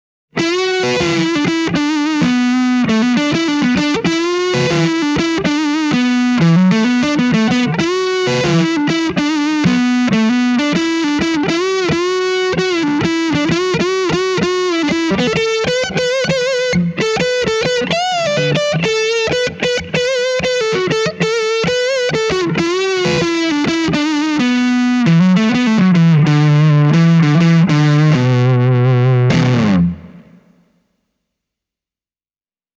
AC10C1:n särösoundien kirjo menee hyvin vahvasti ”klassisen” puolelle. Tämä ei ole mikään high gain -kombo, vaan Voxin luontevin ympäristö ovat 60- ja 70-luvun Pop-, Blues- ja Rock-soundit.
Stratocaster, Gain – kello 3:
Casino, Gain – kello 1:
Hamer, Gain – kello 1: